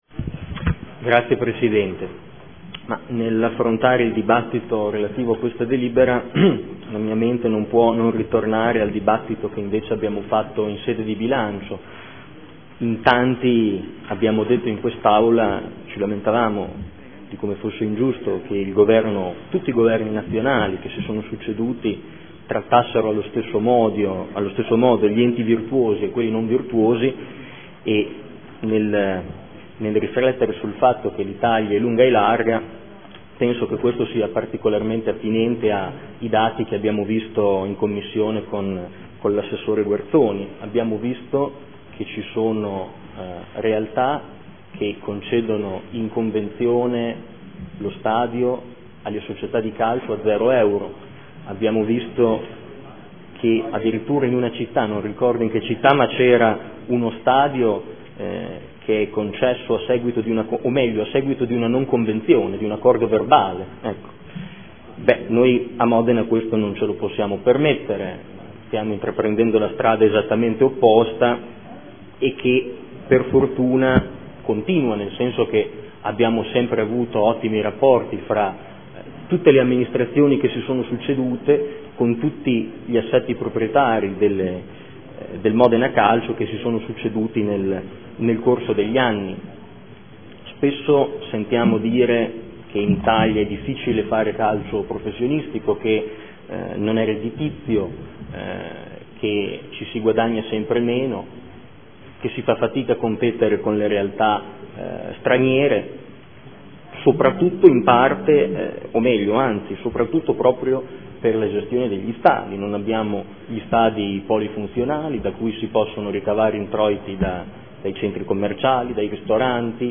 Marco Forghieri — Sito Audio Consiglio Comunale
Seduta del 19/03/2015 Delibera. Convenzione per la gestione dello Stadio comunale Alberto Braglia al Modena FC S.p.a. – Modificazioni e prolungamento durata. Dibattito.